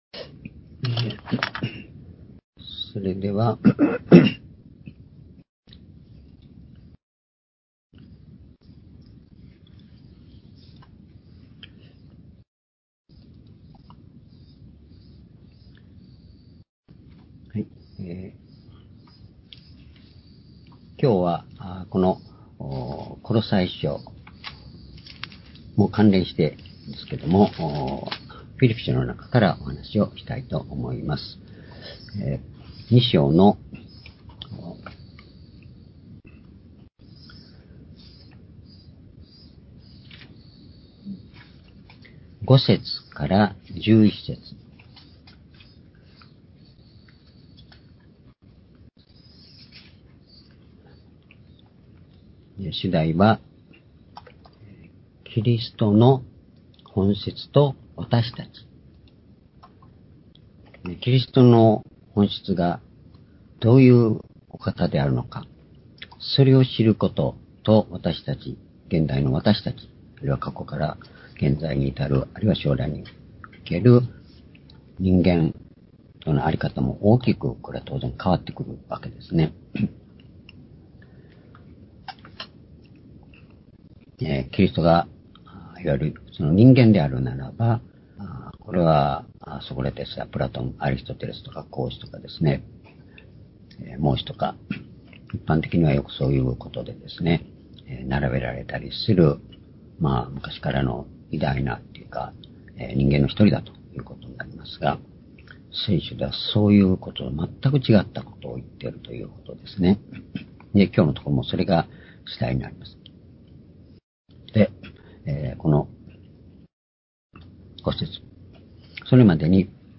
主日礼拝日時 2020年8月23日（主日礼拝） 聖書講話箇所 「キリストの本質と私たち」 フィリピ書2章5節～11節 ※視聴できない場合は をクリックしてください。